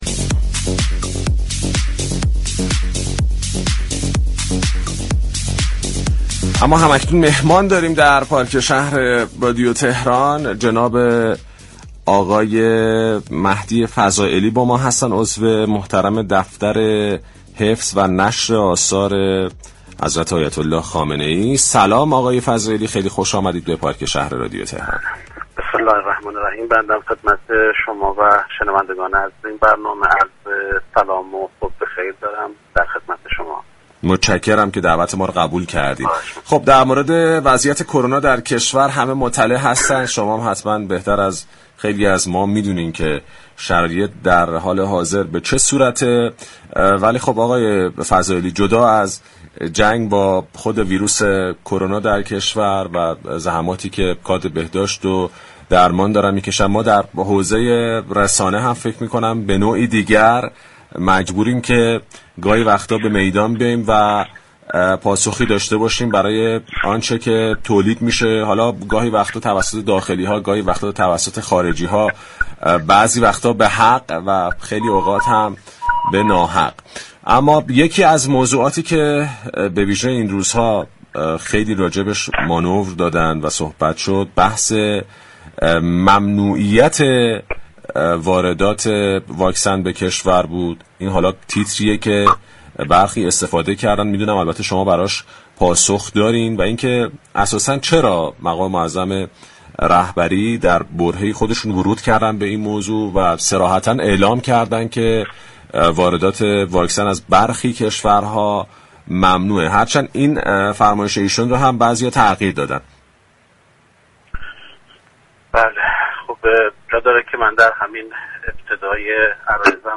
در گفتگو با برنامه پارك شهر رادیو تهران